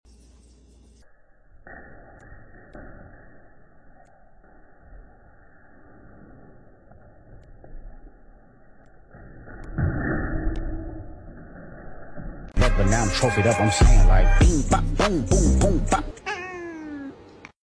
Cat Fall From fridge Funny sound effects free download